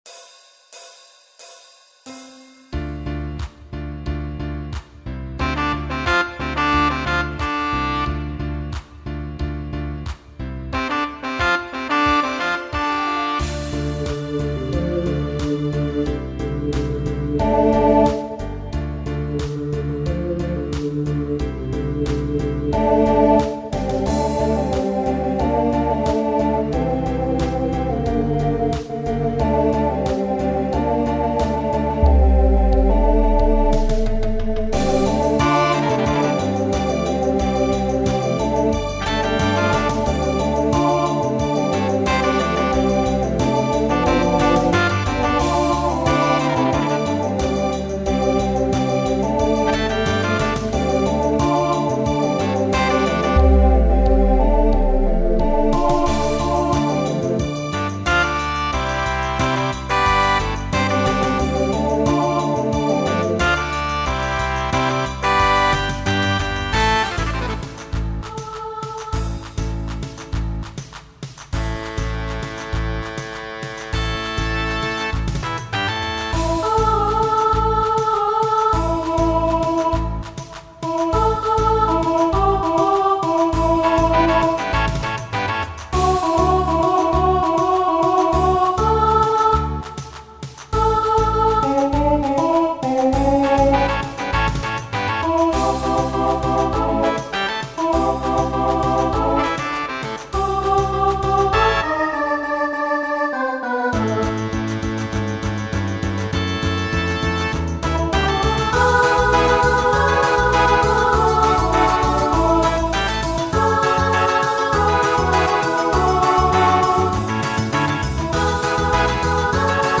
Voicing Mixed